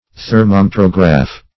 Thermometrograph \Ther`mo*met"ro*graph\, n. [Thermo- + Gr.